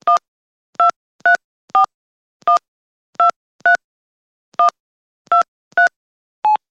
Звуки набора номера телефона
Набор номера - Альтернативный вариант